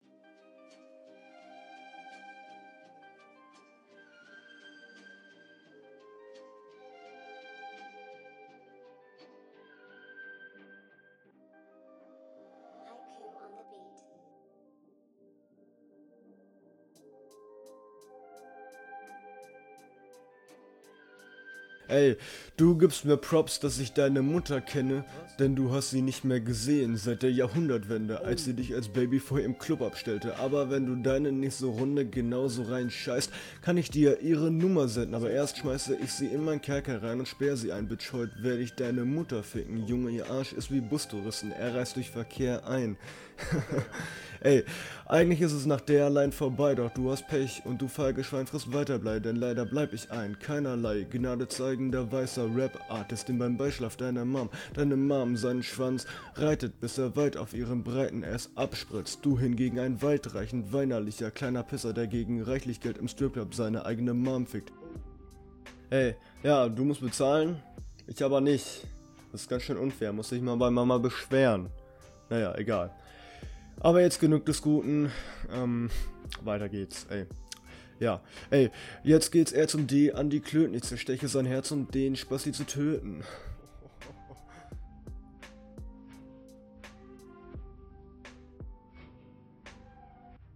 Die Qualität hat noch keiner so scheiße hingekriegt PROBS